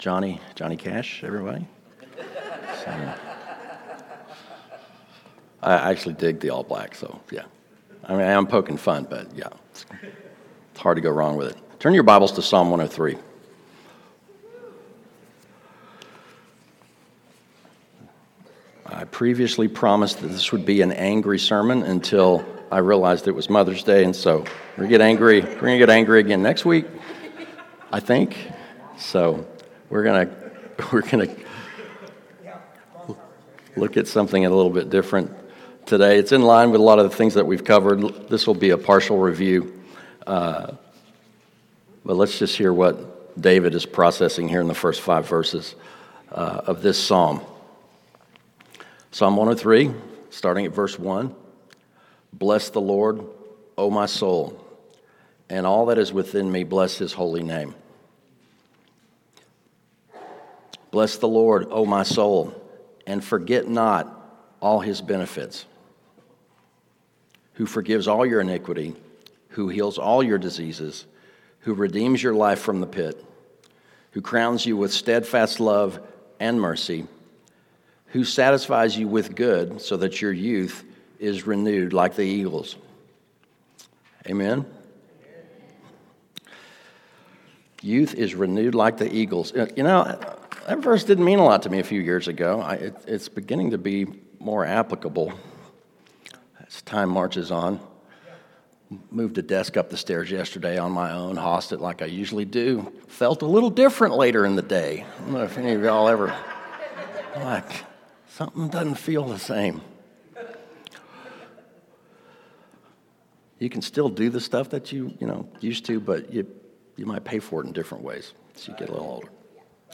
Mother’s Day Sermon